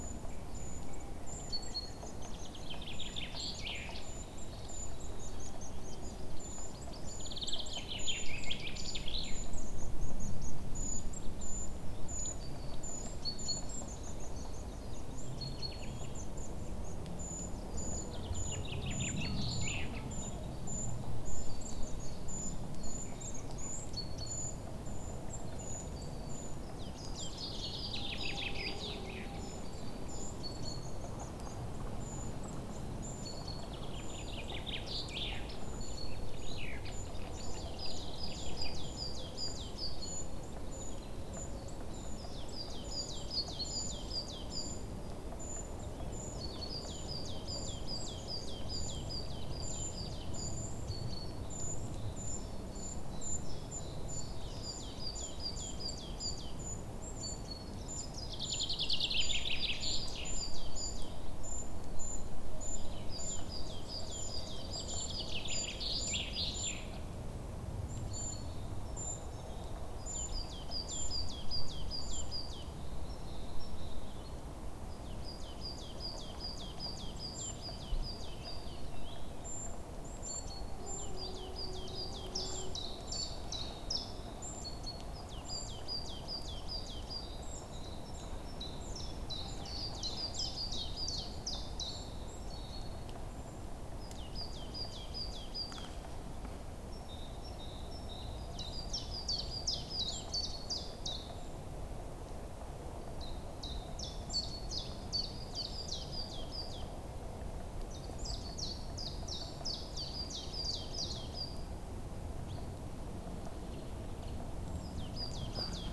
mixkit-european-spring-forest-ambience-1219.wav